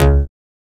BAS_Bass Kraft 1.wav